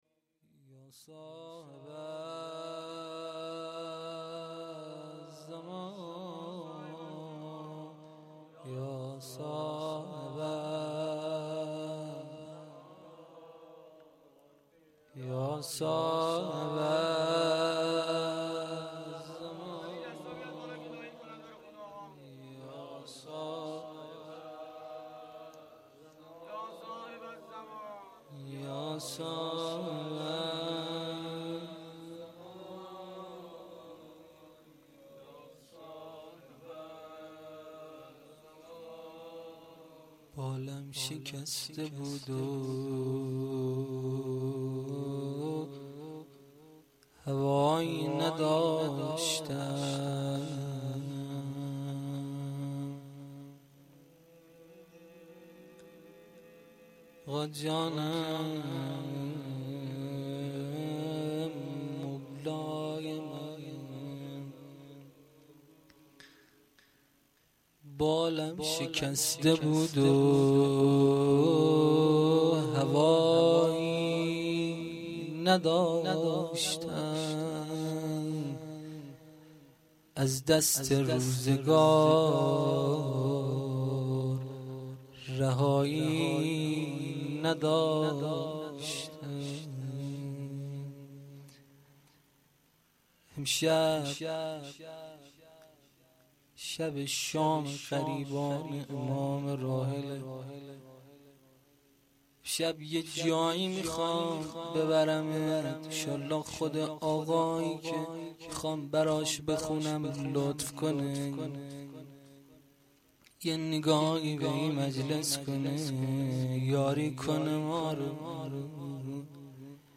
گزارش صوتی جلسه رحلت امام
روضه
roze.mp3